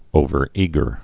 (ōvər-ēgər)